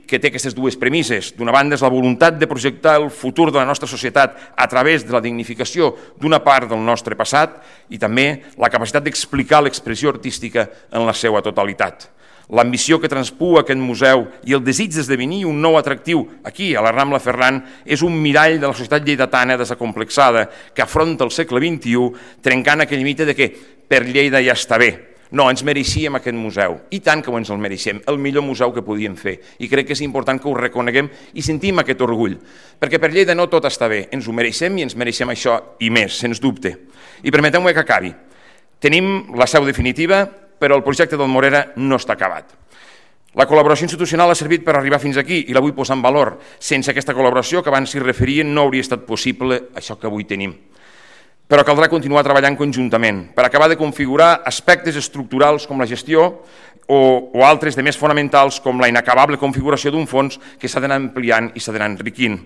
Tall de veu del paer en cap, Fèlix Larrosa, sobre la inauguració del nou MORERA.